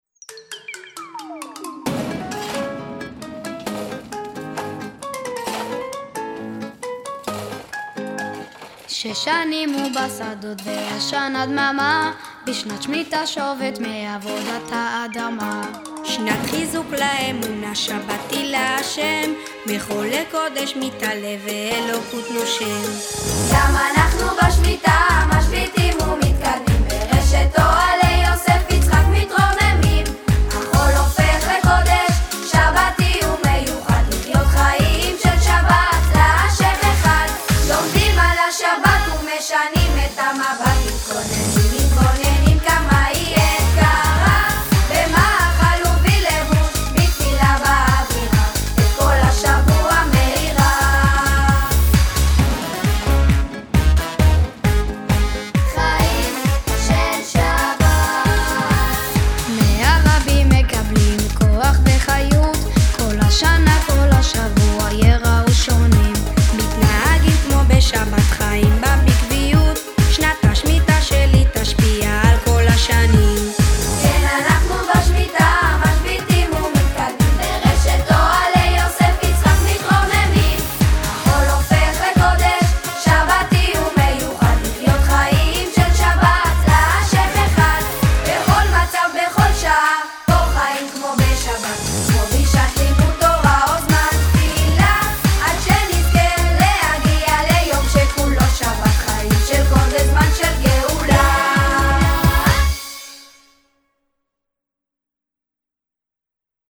המנון
שיר